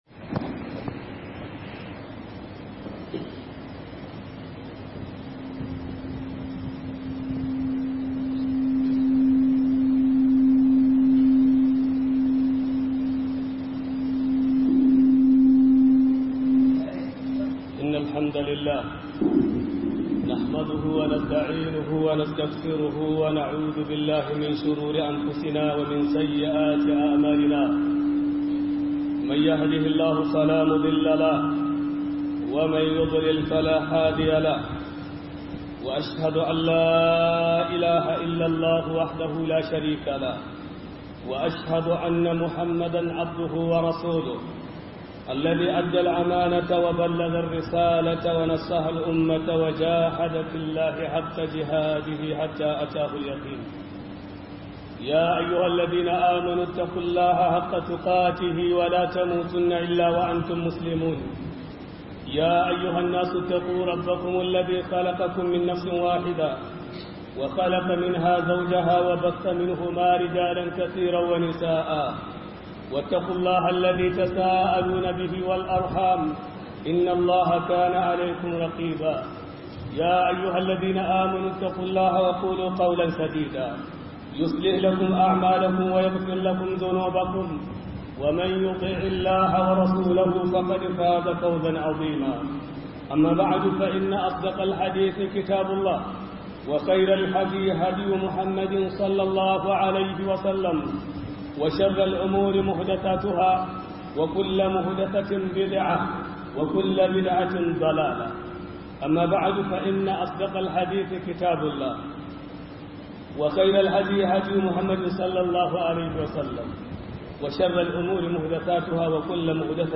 خطبة في استقبال العيد الأضحى